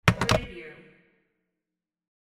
Phone receiver hang up sound effect .wav #5
Description: The sound of hanging up a telephone receiver
Properties: 48.000 kHz 24-bit Stereo
phone-receiver-hang-up-preview-5.mp3